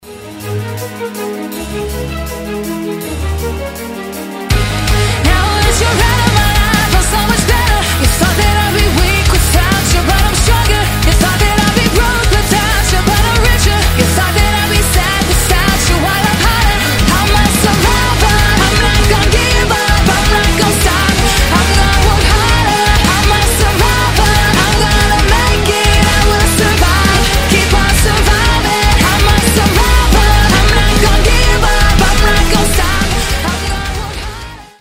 громкие
Драйвовые
Alternative Metal
Alternative Rock
энергичные
быстрые
бодрые
кавер